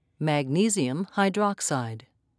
(mag-nes'i-um)